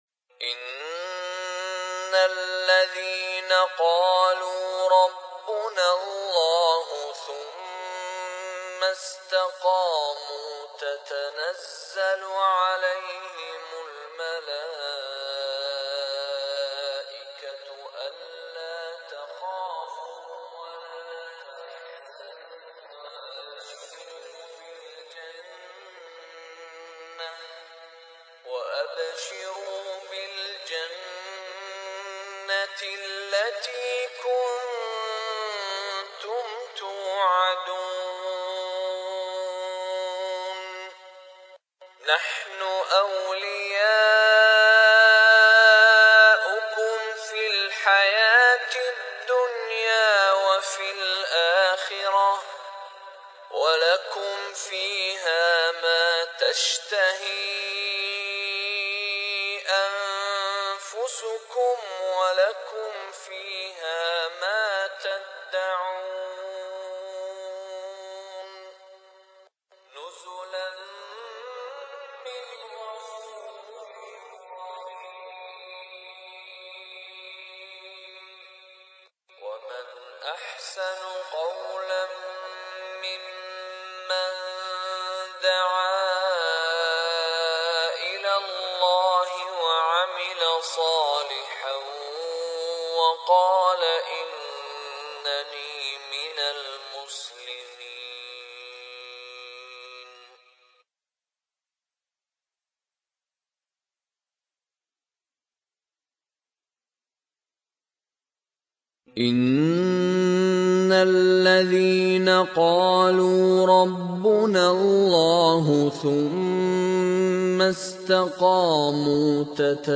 The Qur'anic Listening Therapy
Perlu diketahui bahwa suara MP3 tersebut sudah direkayasa sedemikian rupa dari suara aslinya, bacaan juga diulang 3 kali, agar sesuai dengan kebutuhan terapi pendengaran.
Awas jangan sampai terbalik, karena suara bacaan dirancang sesuai dengan spesifikasi telinga kanan dan kiri manusia.